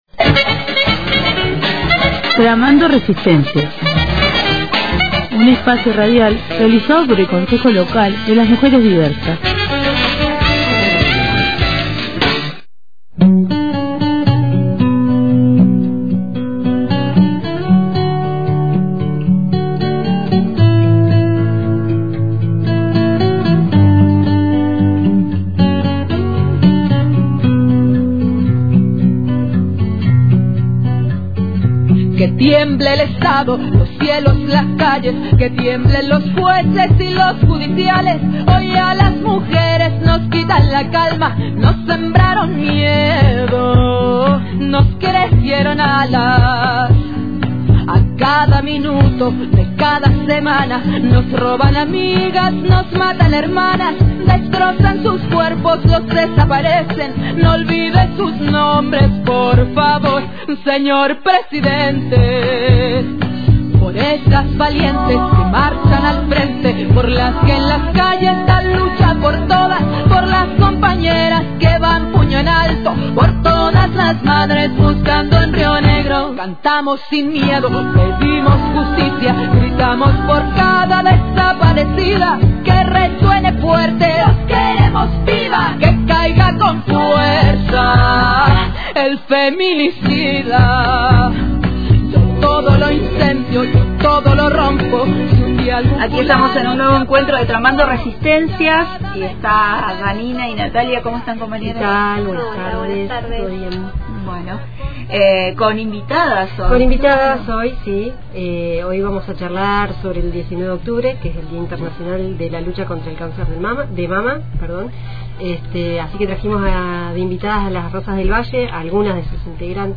En esta ocasión, estuvieron presentes en el estudio «Las rosas del Valle», una asociación civil que trabaja por la prevención y la concientización del cáncer de mama .